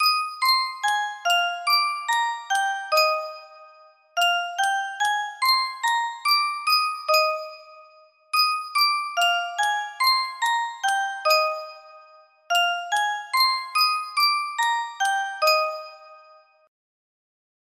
Yunsheng Music Box - Whittington Chimes 4559 music box melody
Full range 60